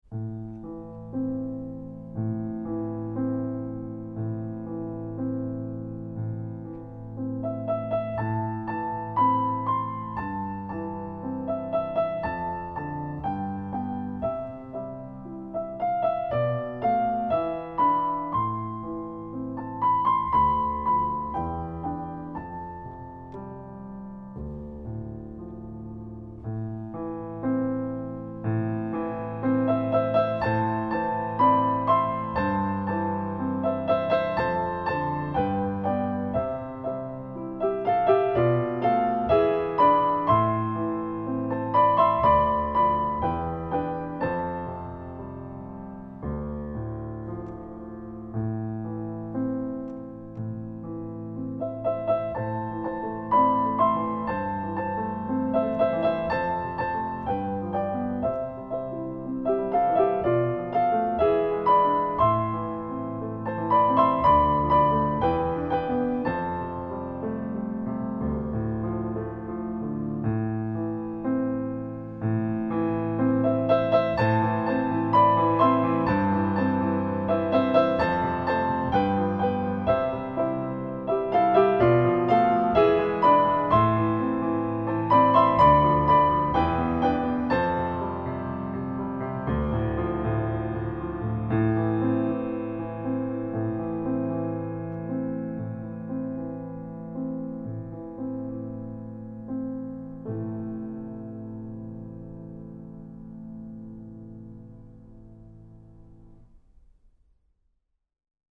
Genre :  ChansonComptine
Style :  Avec accompagnement
une chanson calme, pleine de douceur
Enregistrement piano seul